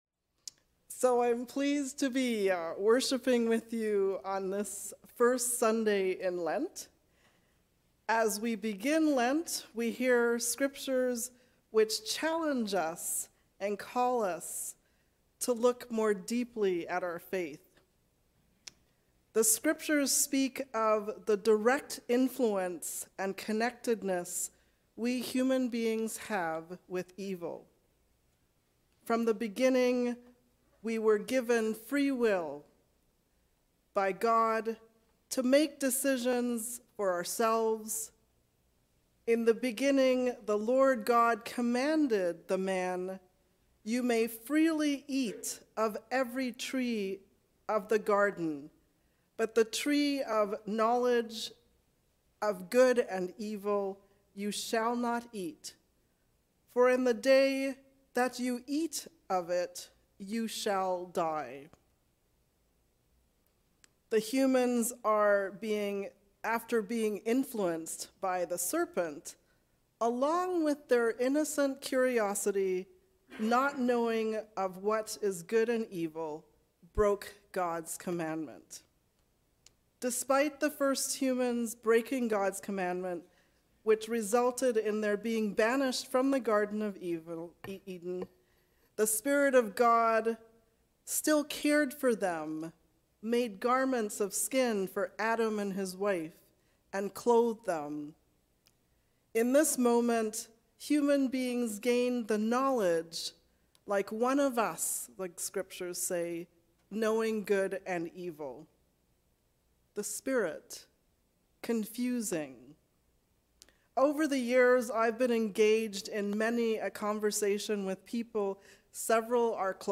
Tempted as we are. A sermon for the First Sunday in Lent